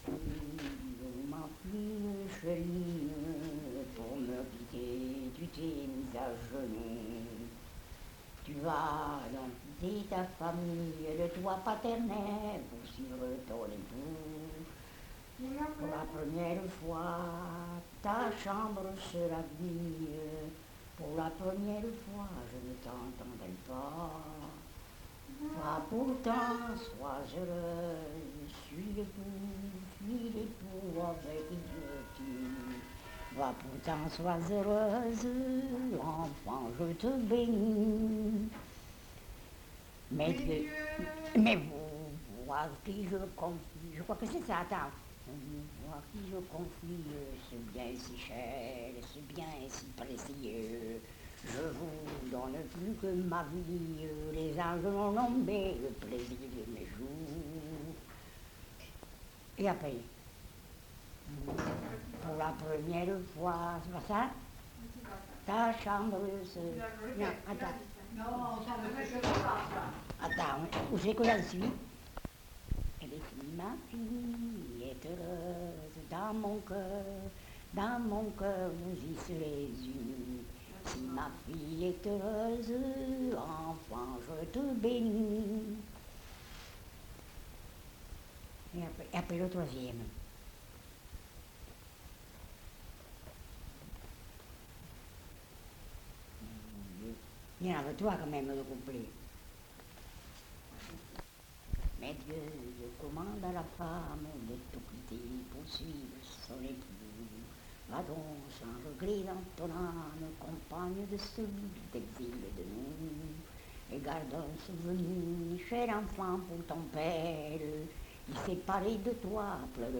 Aire culturelle : Cabardès
Lieu : Mas-Cabardès
Genre : chant
Effectif : 1
Type de voix : voix de femme
Production du son : chanté